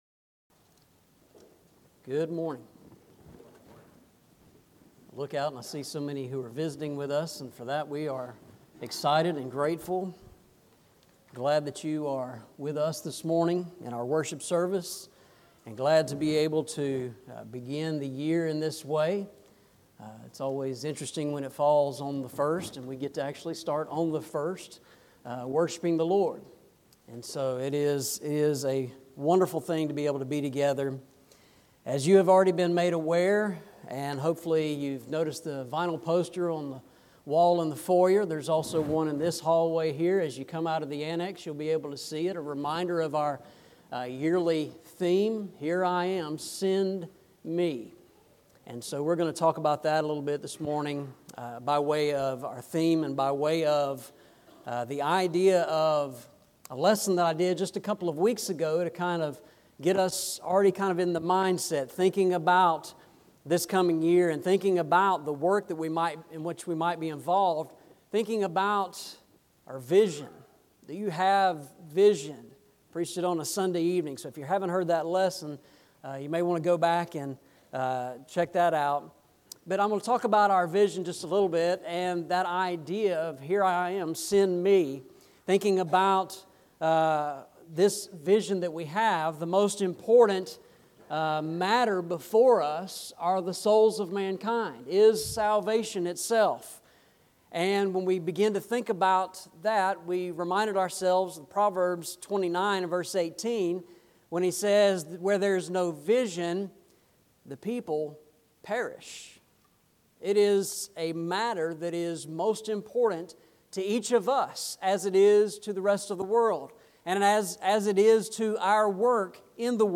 Eastside Sermons Passage: Isaiah 6:1-8 Service Type: Sunday Morning « Scripture